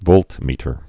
(vōltmētər)